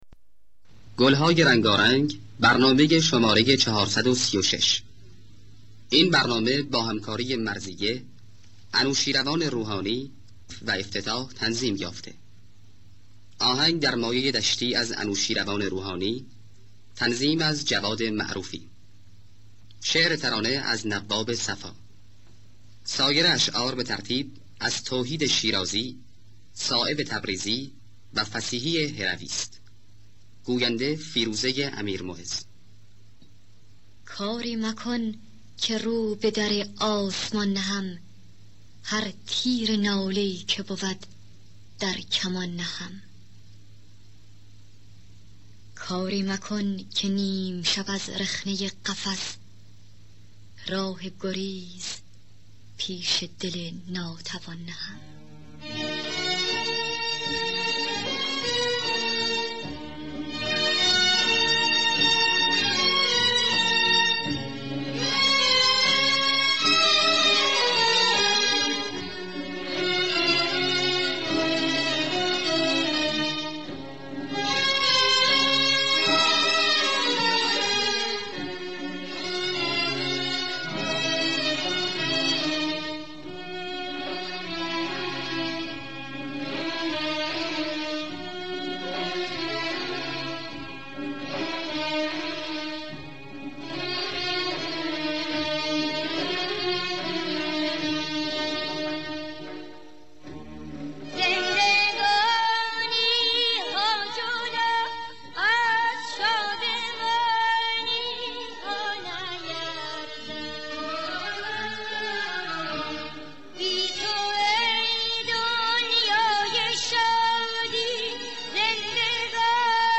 خوانندگان: مرضیه نوازندگان